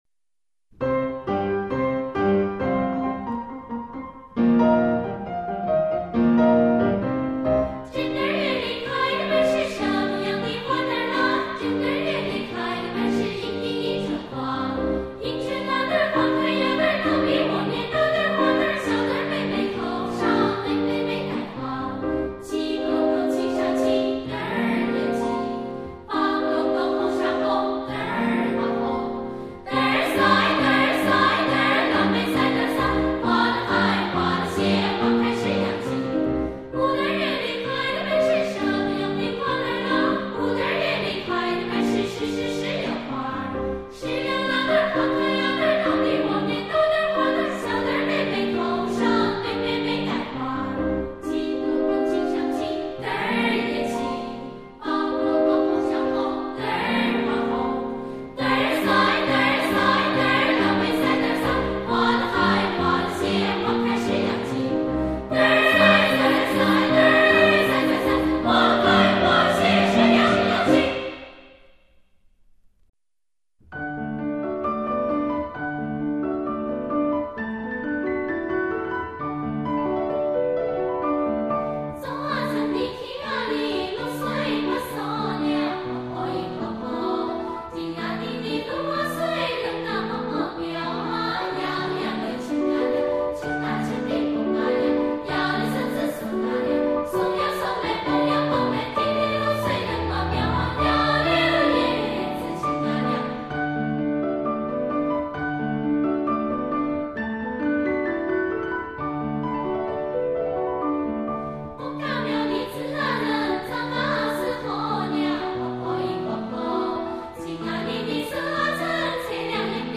(江苏民歌)